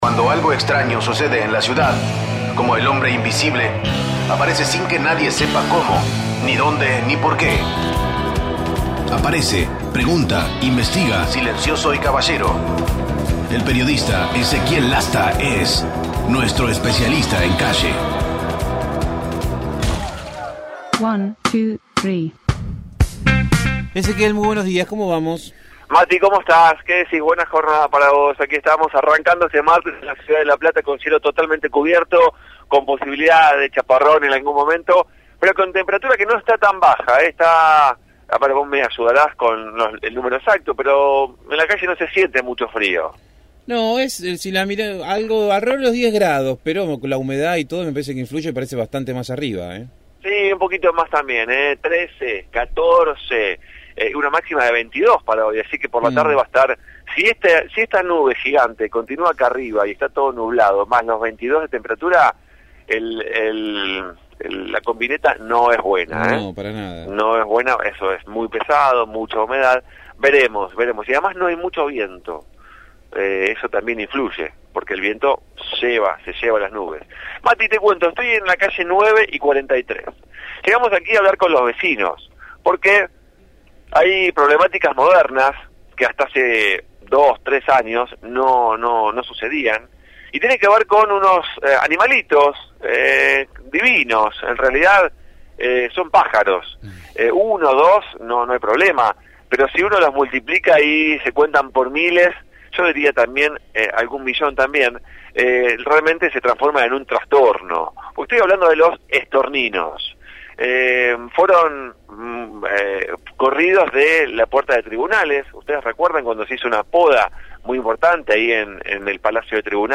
MÓVIL/ Regreso de los estorninos al centro de la ciudad